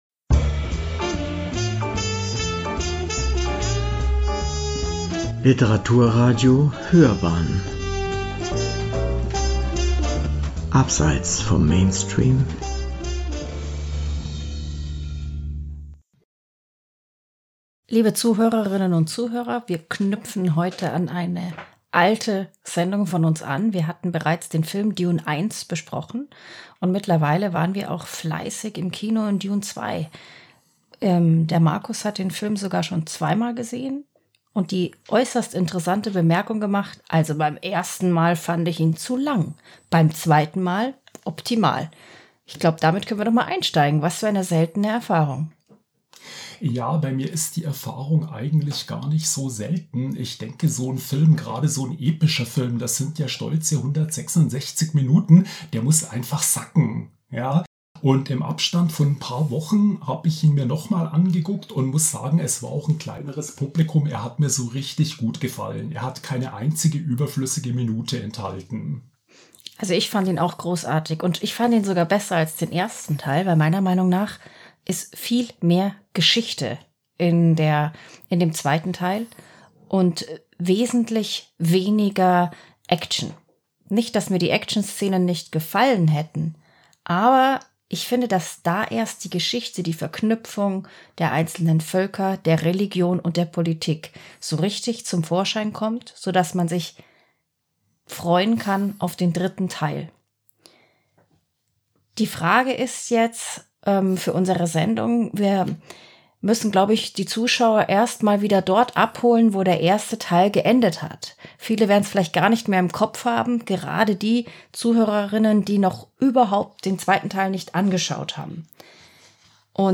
Nach dem überwältigenden Erfolg von Denis Villeneuves erstem „Dune“-Film waren die Erwartungen an die Fortsetzung immens. In diesem Gespräch geben wir einen Einblick, ob „Dune 2“ diesen hohen Ansprüchen gerecht wird.